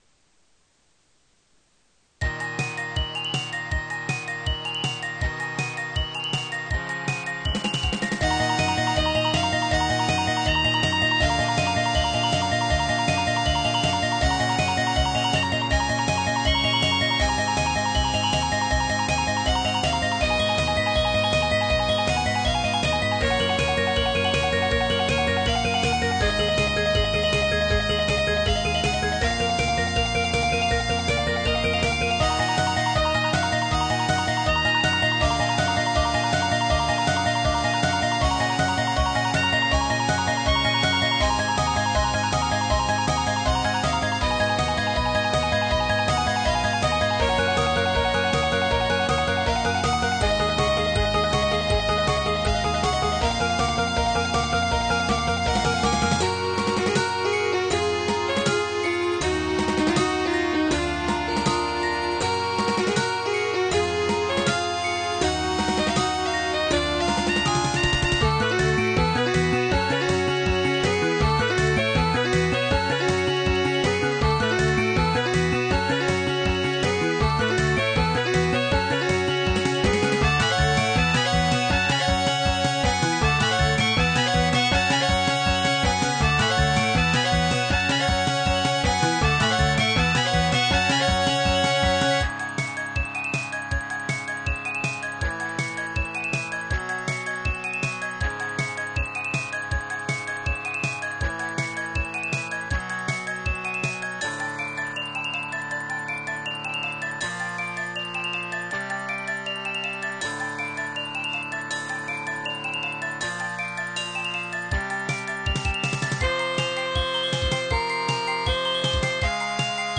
MSGS音源のmidi録音だけど元のmidiは壺のゲームに入ってたやつ